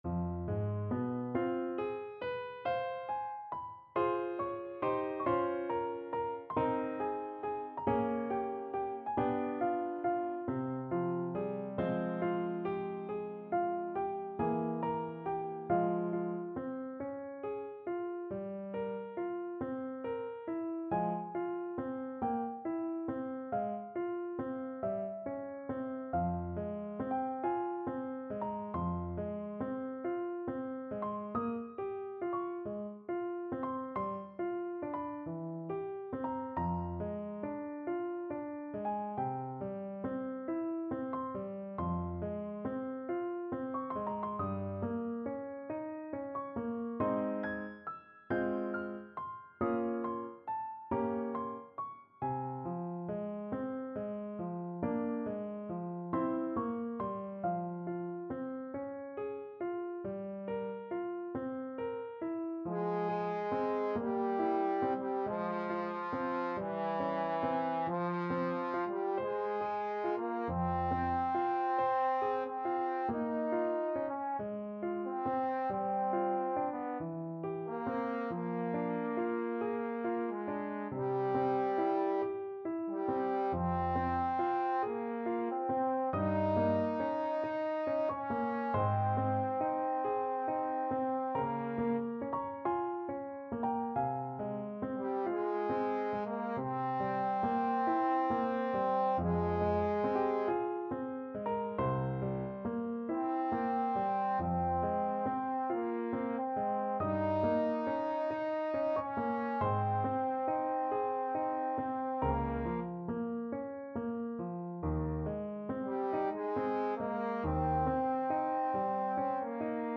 Trombone version
4/4 (View more 4/4 Music)
Slow =c.46
Classical (View more Classical Trombone Music)